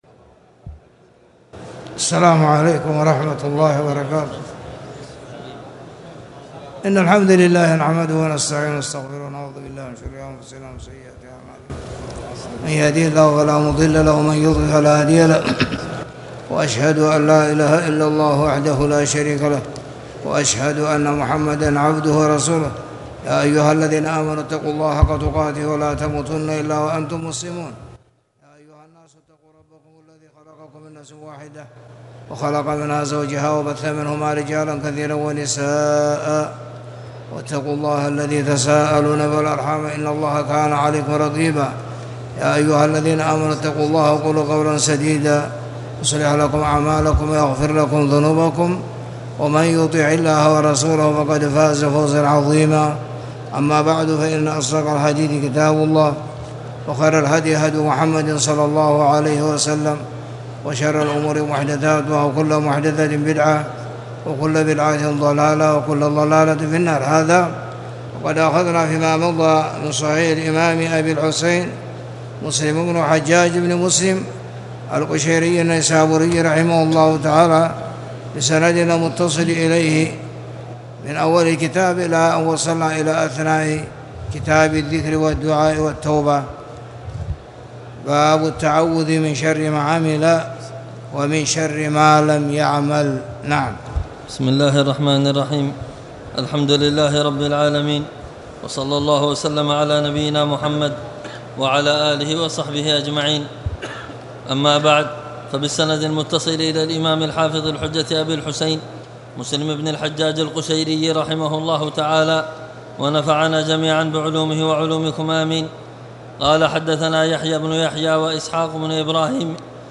تاريخ النشر ١٤ جمادى الآخرة ١٤٣٨ هـ المكان: المسجد الحرام الشيخ